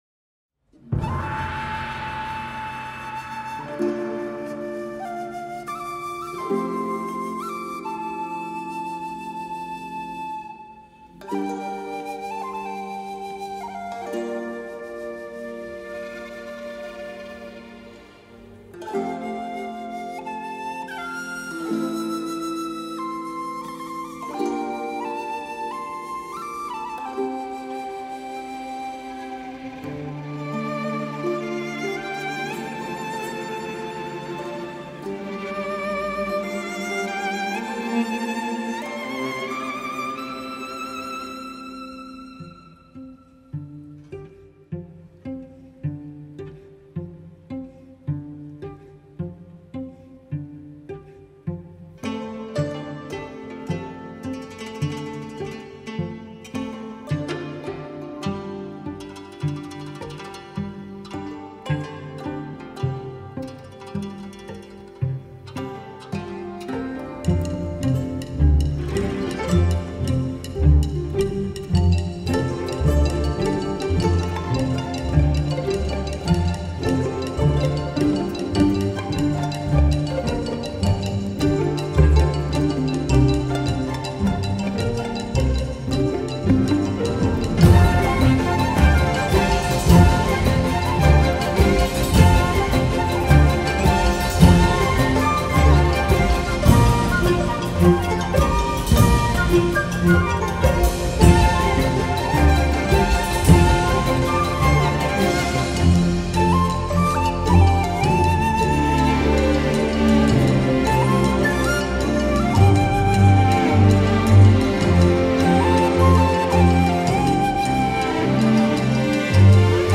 parts for orchestra concert performance
Live-Performance